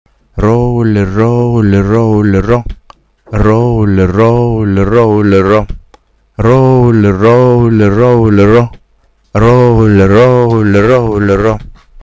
Ищу то, что сам изобразил в этом файле.